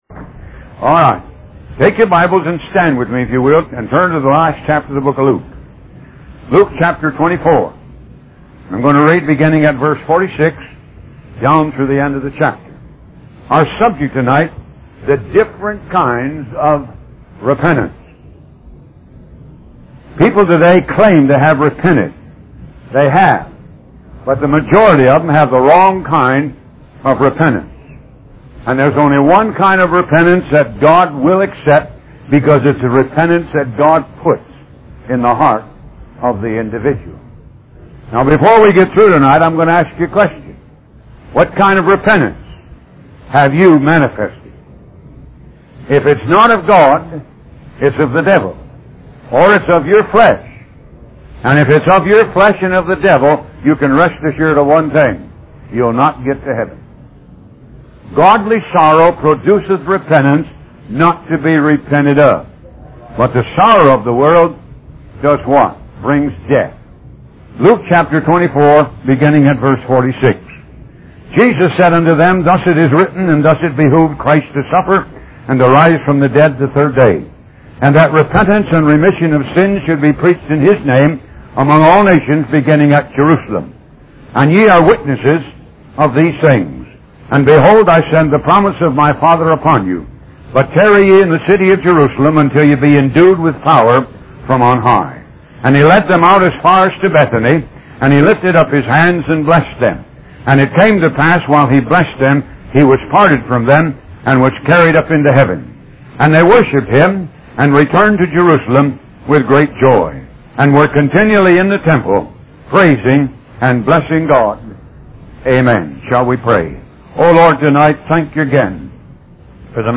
Talk Show Episode, Audio Podcast, Moga - Mercies Of God Association and Different Kinds Of Repentance on , show guests , about Different Kinds Of Repentance, categorized as Health & Lifestyle,History,Love & Relationships,Philosophy,Psychology,Christianity,Inspirational,Motivational,Society and Culture